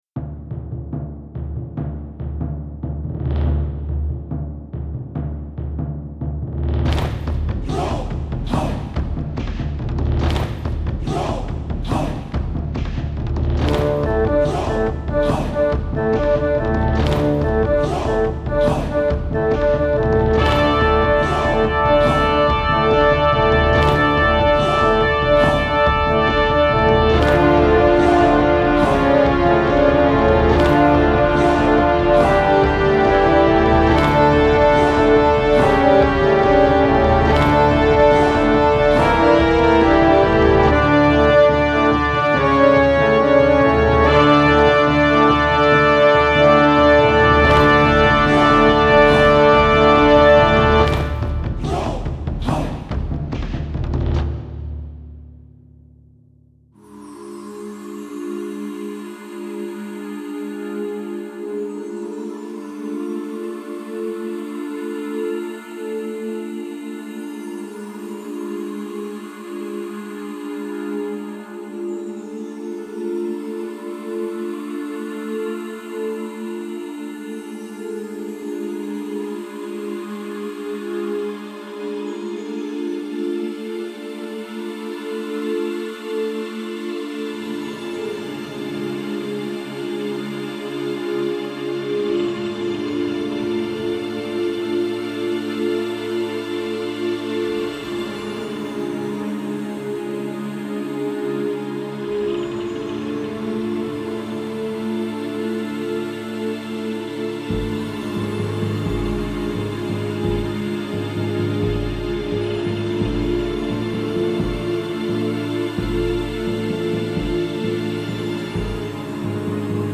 Something original I’m working on. Current synopsis: Your character has just been shipwrecked into a woodsy area, you start exploring your surroundings, find a small clearing to a cave perhaps, then the monsters come out!